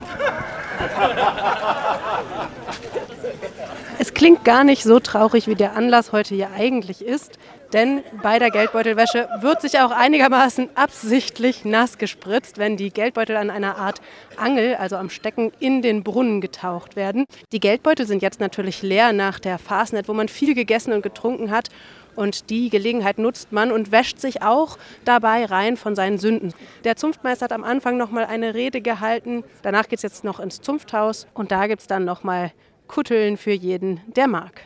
Los ging´s am Zunfthaus, und mit Trommelschlägen sind sie feierlich zum Narrenbrunnen in die Altstadt gezogen.
Geldbeutelwäsche in Rottenburg.